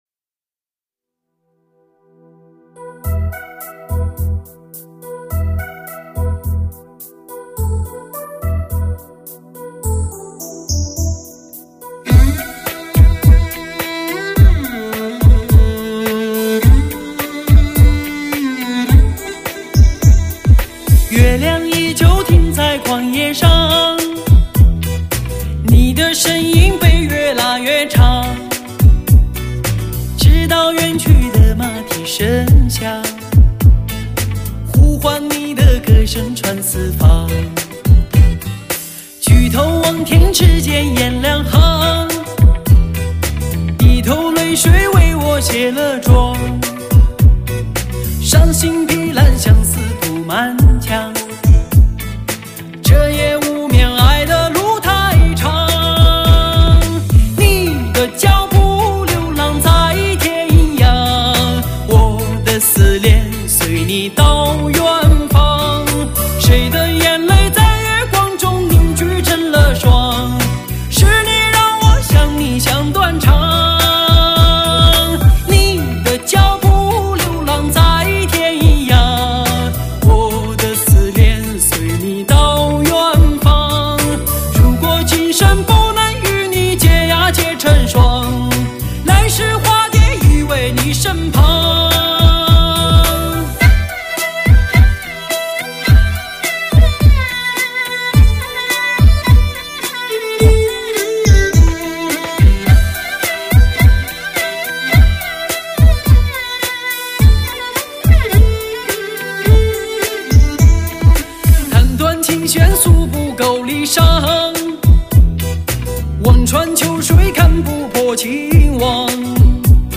抒发心情放松的解药 抚慰心灵的疗伤HIFI音乐